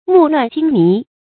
目亂精迷 注音： ㄇㄨˋ ㄌㄨㄢˋ ㄐㄧㄥ ㄇㄧˊ 讀音讀法： 意思解釋： 眼花繚亂，神情迷惑。